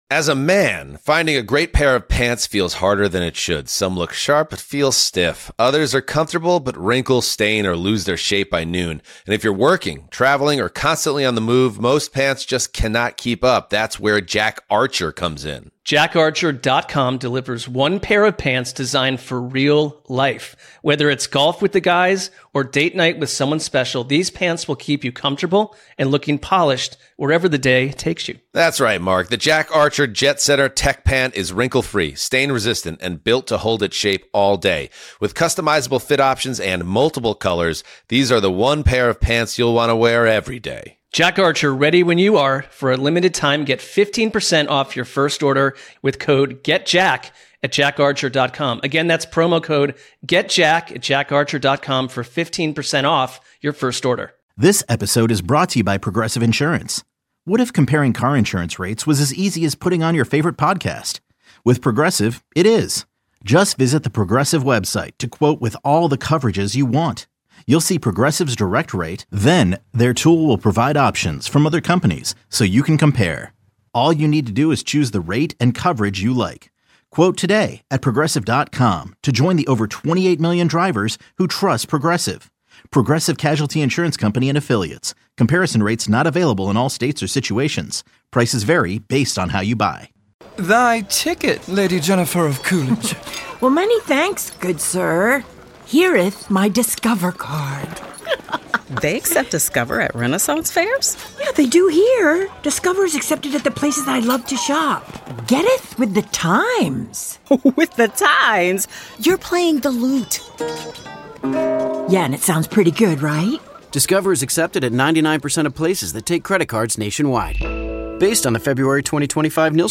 2-5-26 Nine2Noon Interview